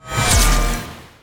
melee-hit-13.ogg